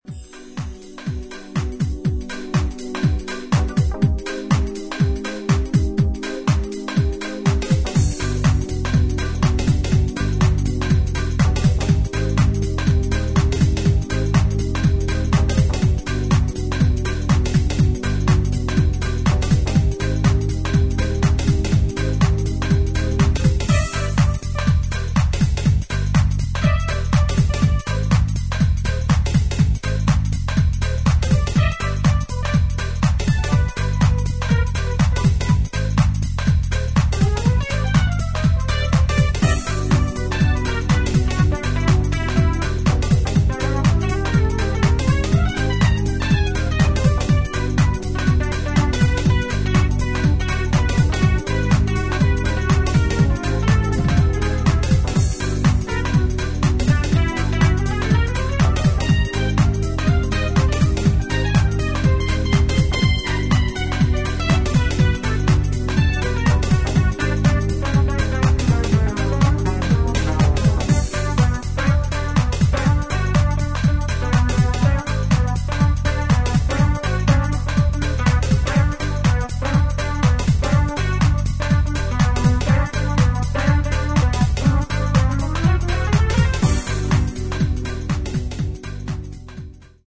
心地よく浮遊感のあるパッドとジャジーなシンセが印象的な